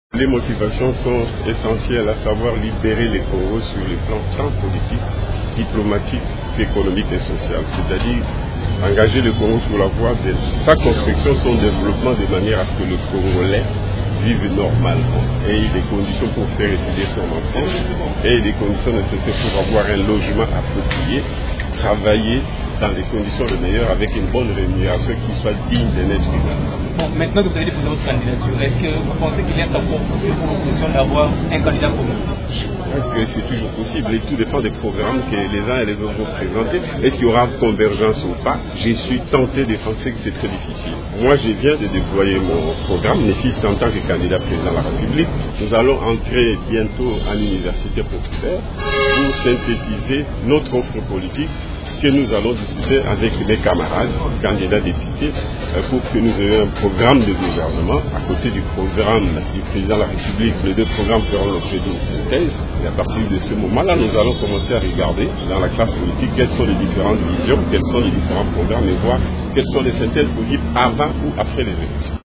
A sa sortie du siège de la Commission électorale nationale indépendante (CENI) ce samedi, l’ancien Premier ministre a expliqué que le développement intégral du Congolais était principale motivation.